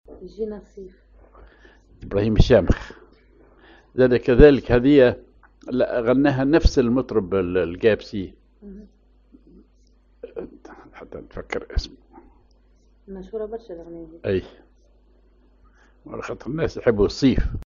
Maqam ar راست
Rhythm ar دويك أو نصف وحدة
genre أغنية